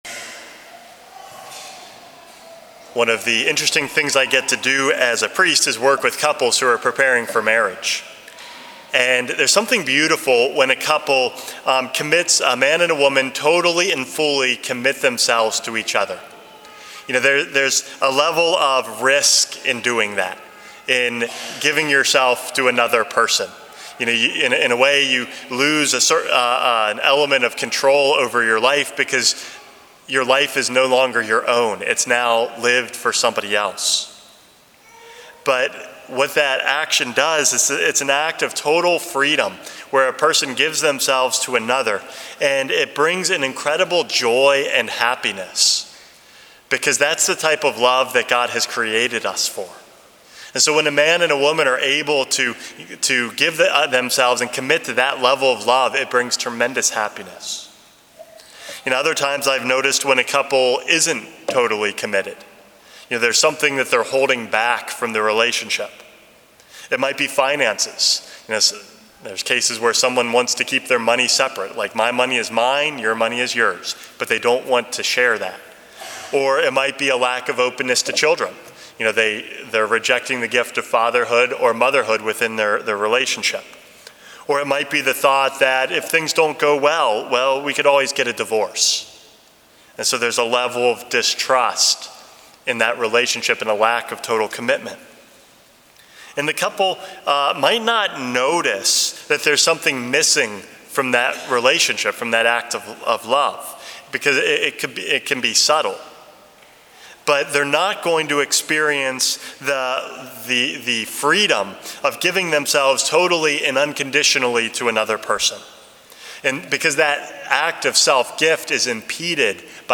Homily #418 - Going All In